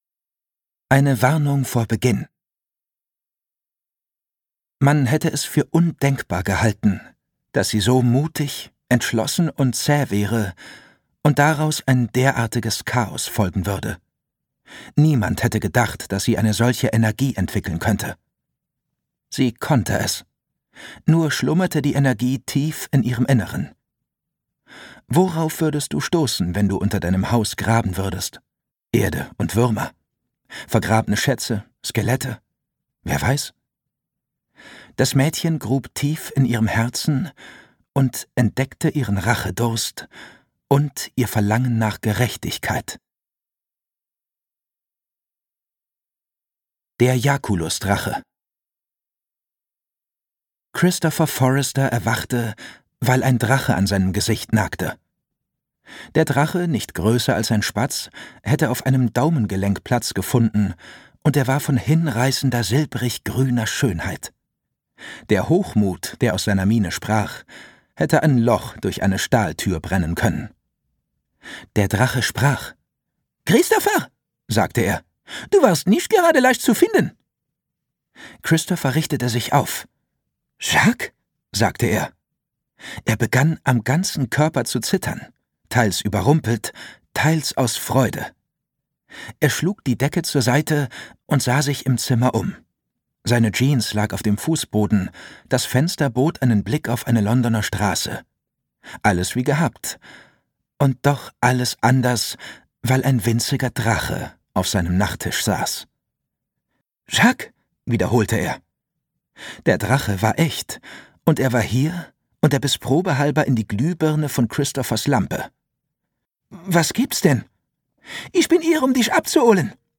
Gekürzt Autorisierte, d.h. von Autor:innen und / oder Verlagen freigegebene, bearbeitete Fassung.
Alle Hörbücher der Serie